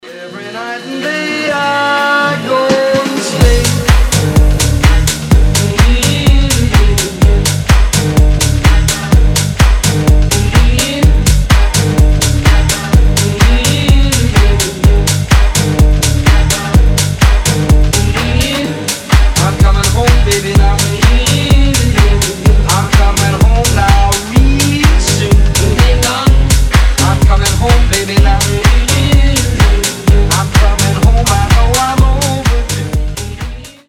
заводные
Funky House
Jackin House